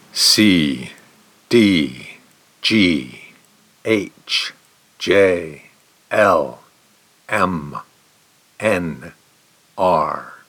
Consonants_no-exhalation.mp3